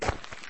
footfall.wav